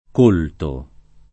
colto [ k 1 lto ]